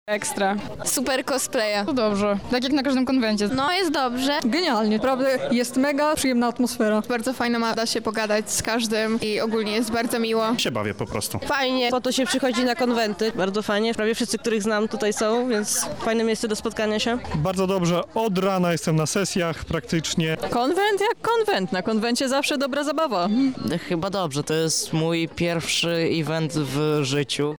Relacja LDF